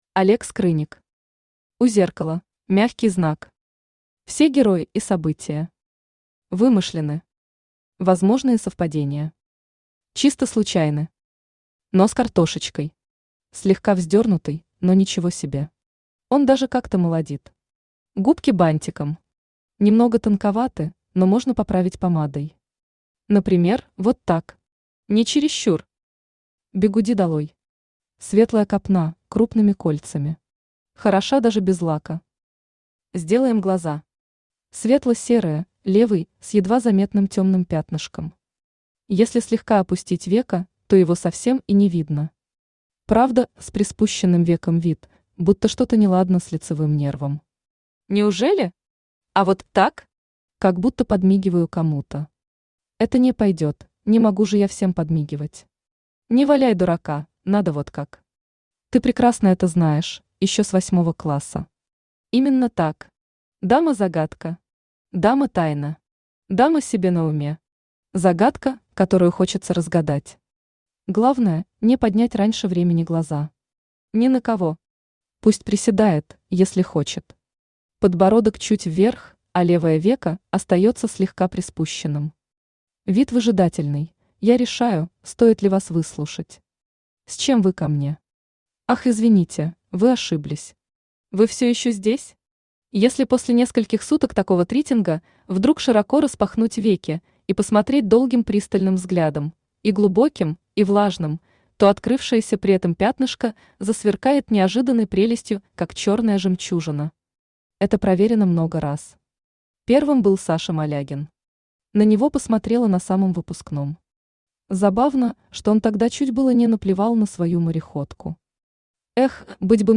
Aудиокнига У зеркала Автор Олег Николаевич Скрынник Читает аудиокнигу Авточтец ЛитРес.